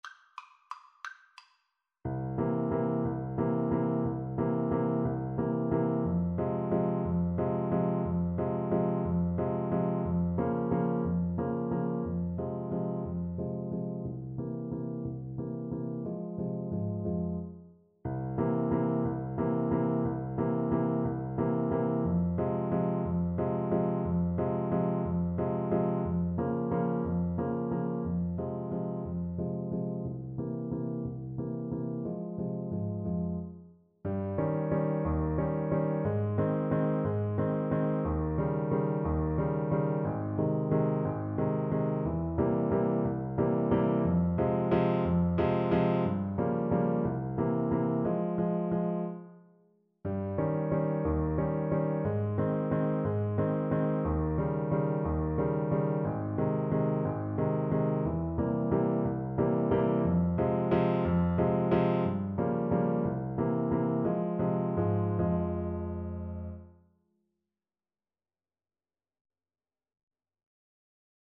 is a waltz
3/4 (View more 3/4 Music)
Waltz . = c.60
Classical (View more Classical Trombone Music)